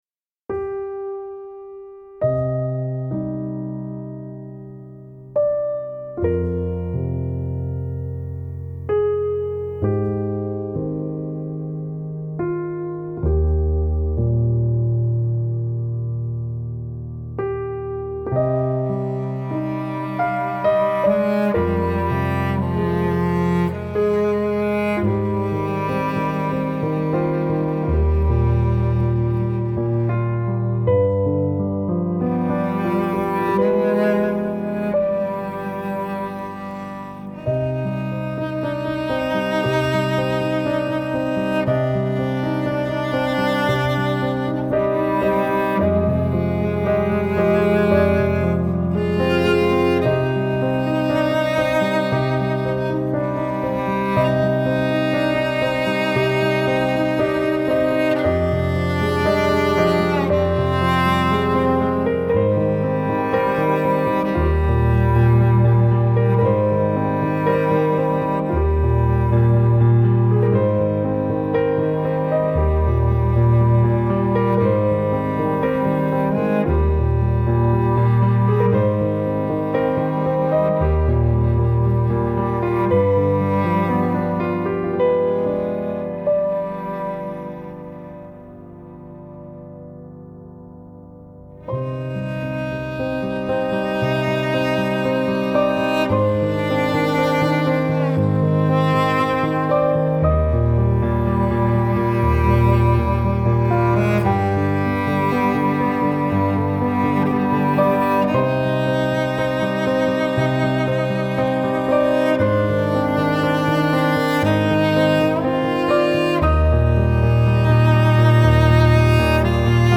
موسیقی کنار تو
سبک آرامش بخش , ابری و بارانی , پیانو , موسیقی بی کلام
موسیقی بی کلام ویولن سل